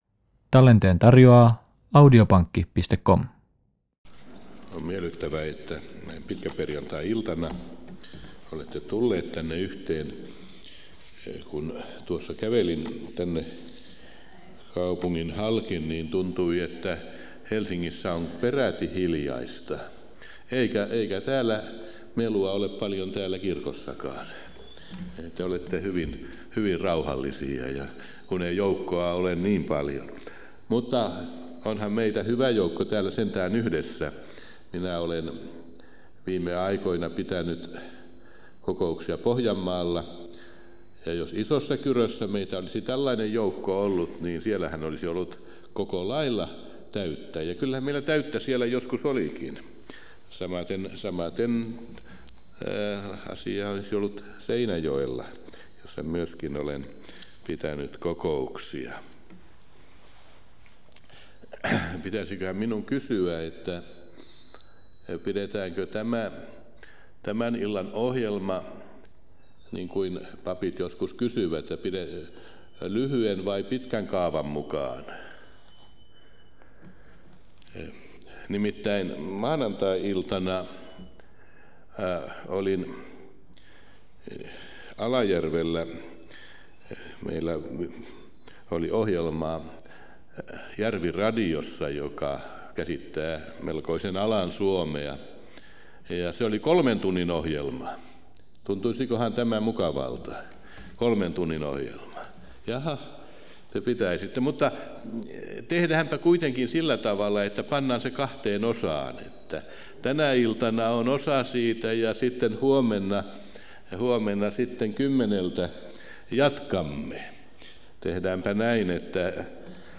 Aitolahdessa 4.9.1976 https